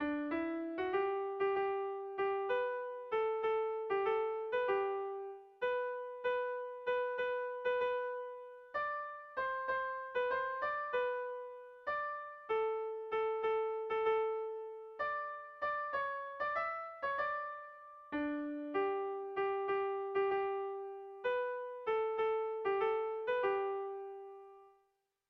Irrizkoa
Oiartzun < Oarsoaldea < Gipuzkoa < Euskal Herria
Zortziko txikia (hg) / Lau puntuko txikia (ip)
ABDE